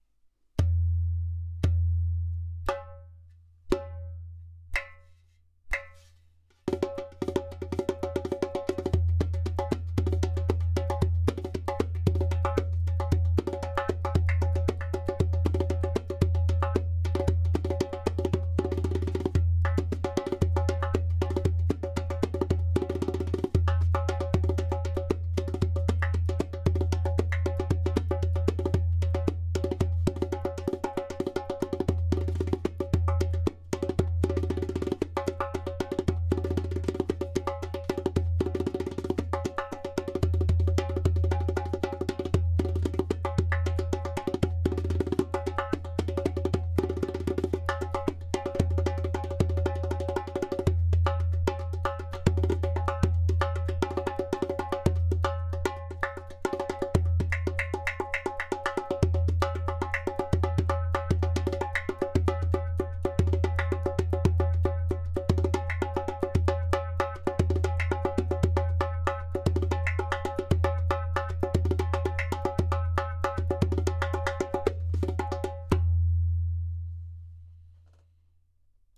115bpm
• Strong and super easy to produce clay kik (click) sound
• Deep bass
• Even tonality around edges.
• Beautiful harmonic overtones.
• Skin: Fish skin